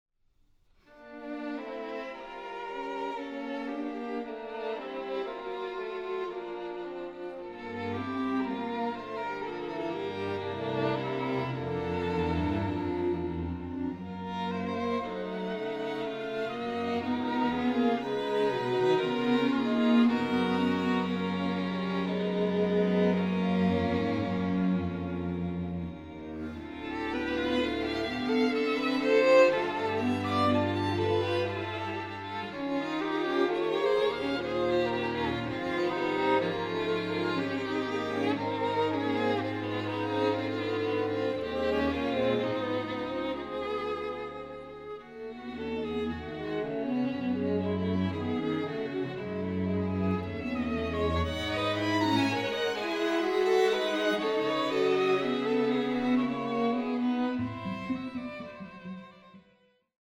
Musik mit Ecken und Kanten, mit Linien und großen Bögen
Allegro